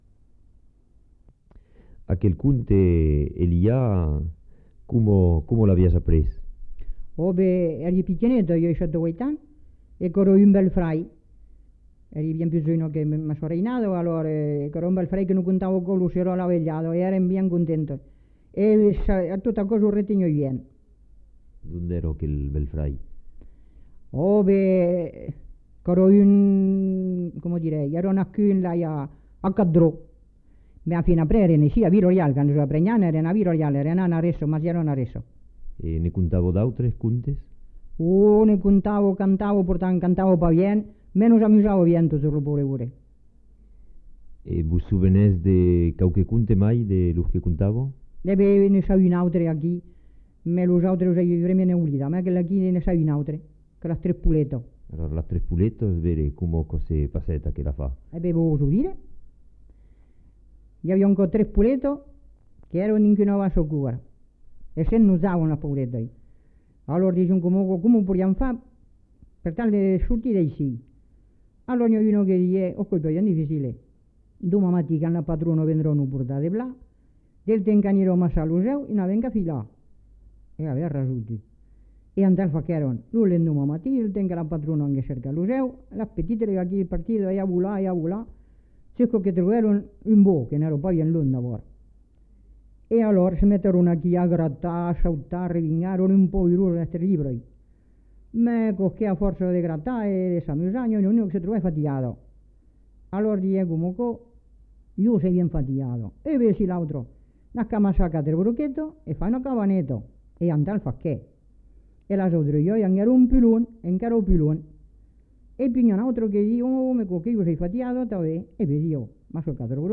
Aire culturelle : Haut-Agenais
Lieu : Villeréal
Genre : conte-légende-récit
Effectif : 1
Type de voix : voix de femme
Production du son : parlé